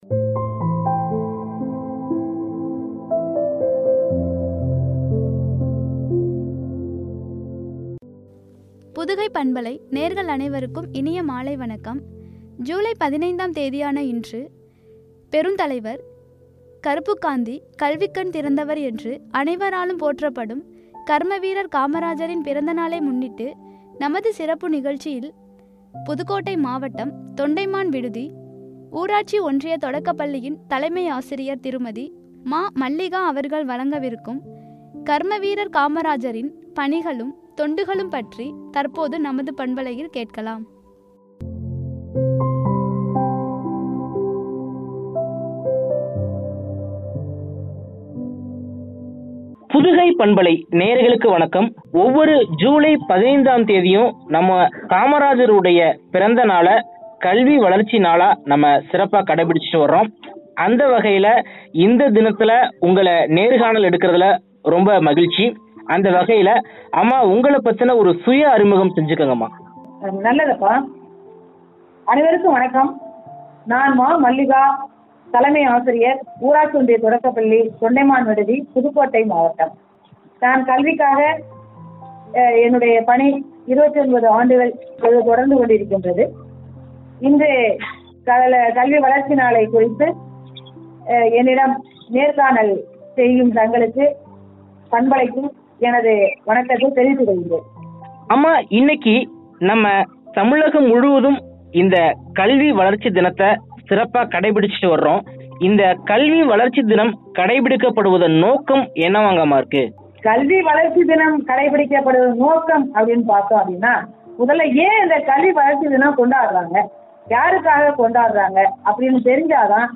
“கர்மவீரர்” காமராஜரின் பணிகளும், தொண்டுகளும்” எனும் தலைப்பில் வழங்கிய உரையாடல்.